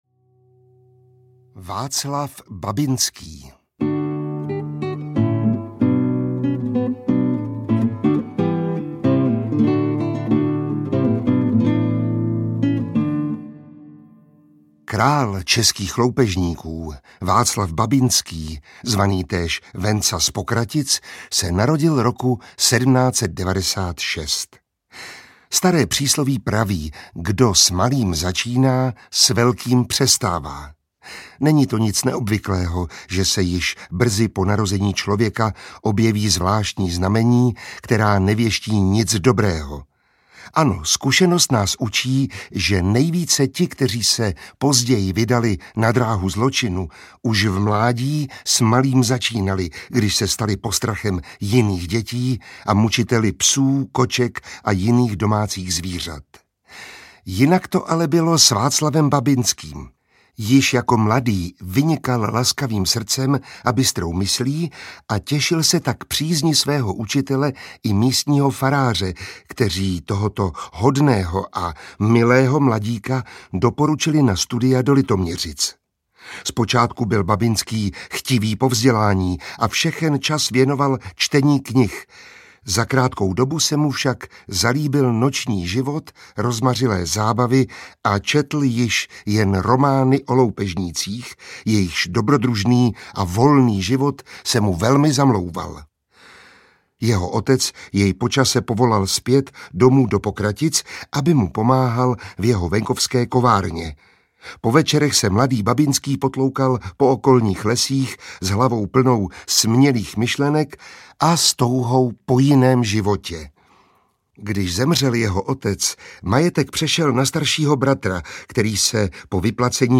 Slavné pověsti a příběhy zbojnické audiokniha
Ukázka z knihy
• InterpretMiroslav Táborský